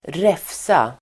Uttal: [²r'ef:sa]